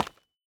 Minecraft Version Minecraft Version 25w18a Latest Release | Latest Snapshot 25w18a / assets / minecraft / sounds / block / calcite / step2.ogg Compare With Compare With Latest Release | Latest Snapshot
step2.ogg